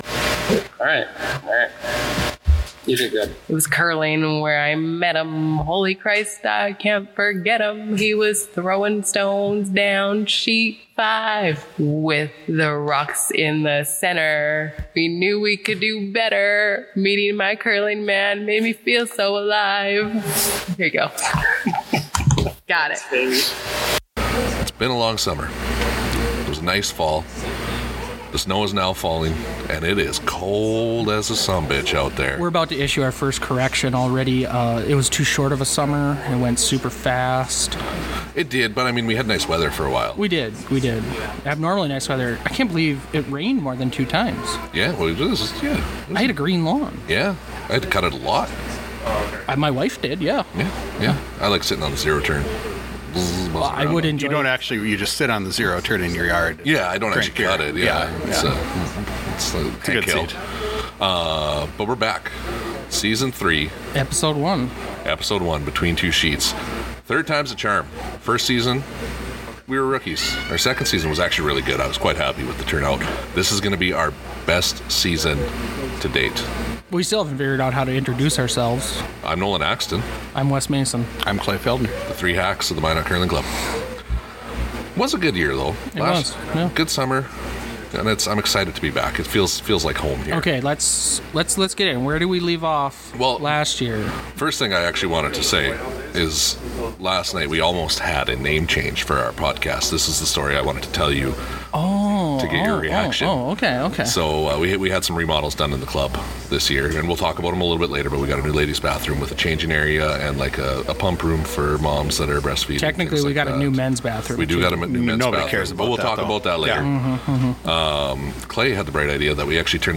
A curling podcast covering general curling topics through discussion and interviews. Focus on club level curling and the people who make curling great by keeping clubs going.